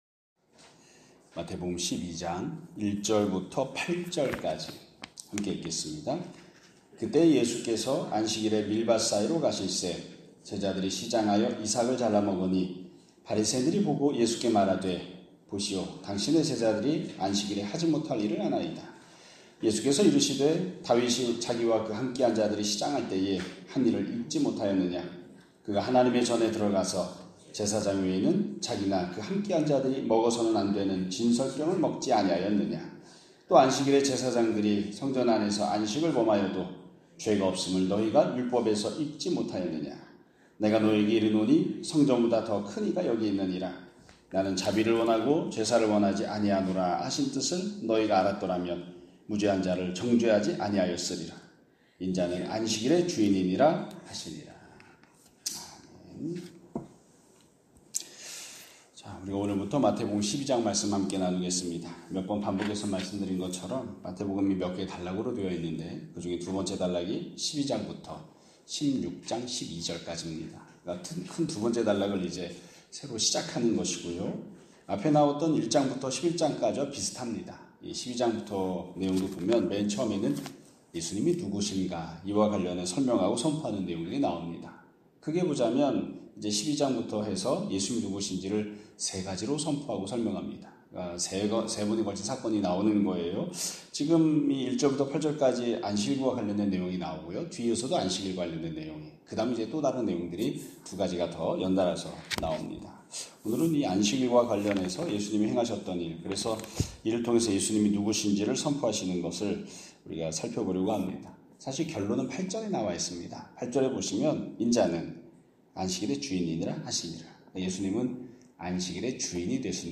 2025년 9월 5일 (금요일) <아침예배> 설교입니다.